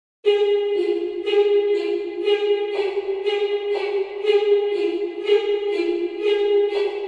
This trending sound effect is perfect for meme creation, gaming & entertainment.